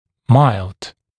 [maɪld][майлд]мягкий, умеренный, слабый, лёгкий